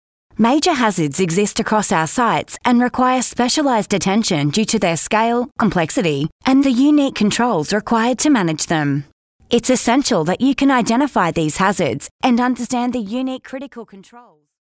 Female
Experienced & Versatile Australian Female Voice:
Described as Engaging, Upbeat, Fun, Fancy, Youth, Professional, Sensual, Caring, Motherly, Lively, Cool, Conversational, News Reader, MTV Host, On Hold, Sincere, Gov, Medical, Upbeat =)